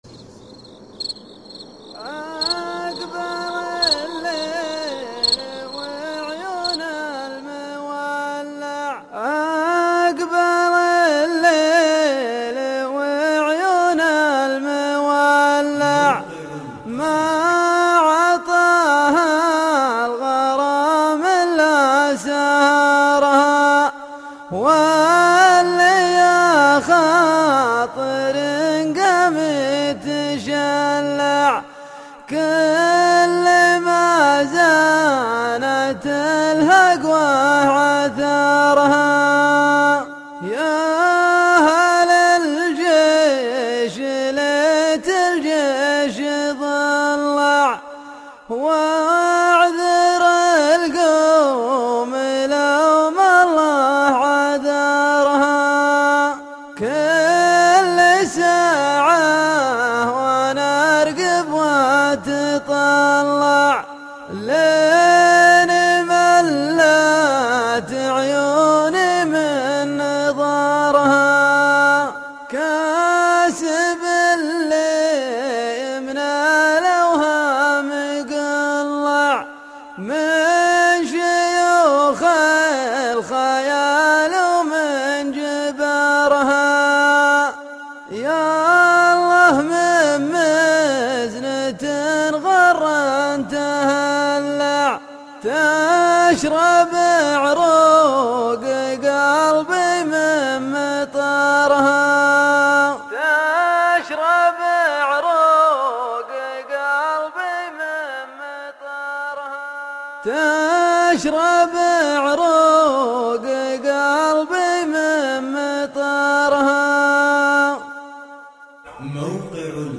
شيله   02 سبتمبر 2011